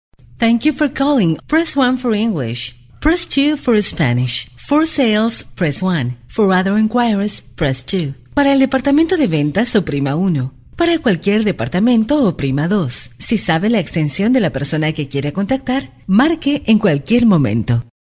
Warm, pleasant, deeply emotive, smooth and comfortable voice. Confident, energetic, professional, Corporate and Institutional.
Sprechprobe: Sonstiges (Muttersprache):